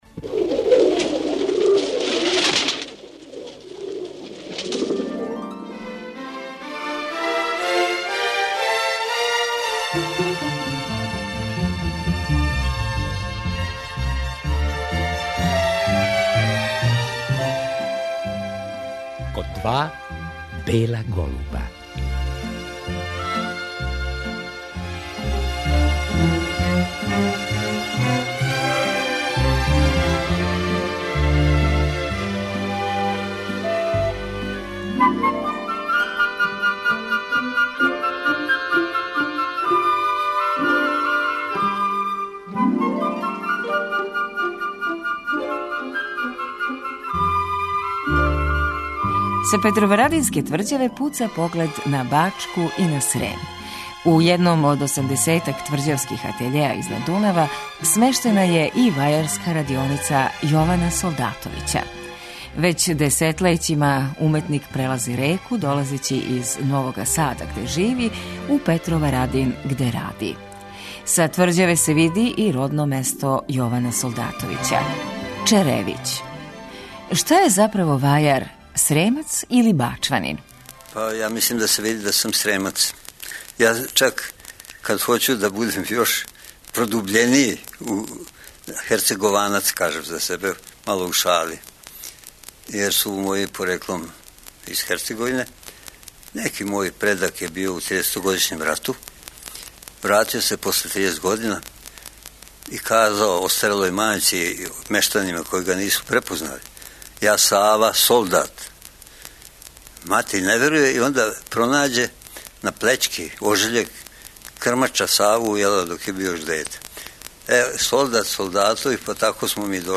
Поводом годишњице рођења Јована Солдатовића, академског вајара и дугогодишњег професора Академије уметности у Новом Саду, слушаћемо разговор који је снимљен 2002. године.